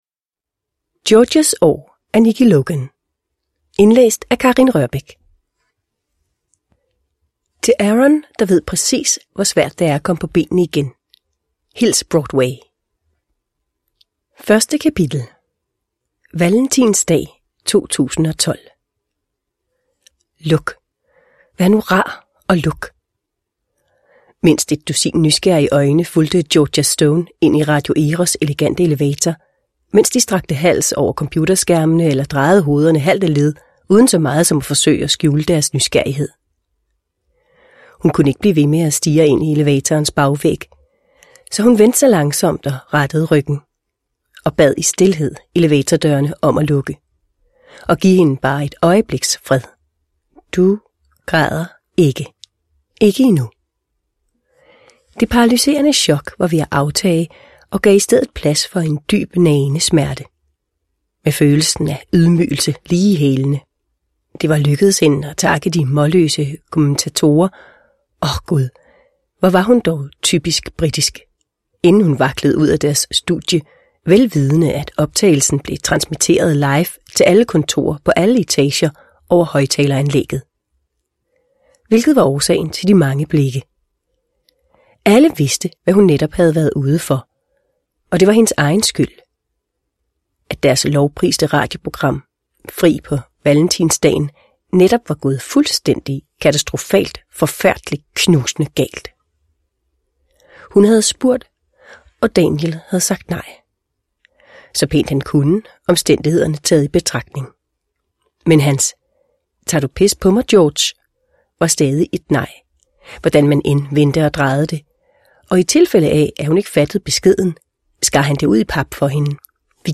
Georgias år – Ljudbok – Laddas ner